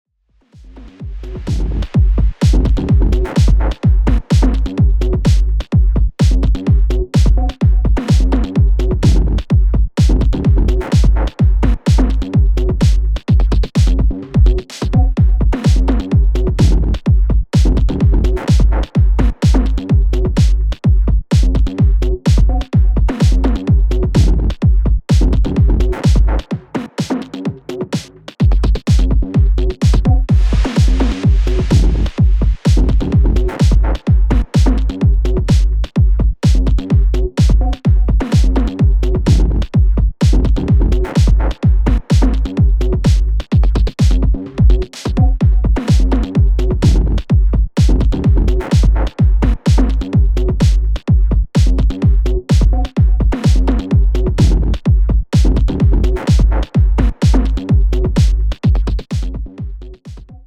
somehow I set tempo to 127 and went on.
Just the loop, but when I like a loop you can most of the times tell that the finished thing is very close sound wise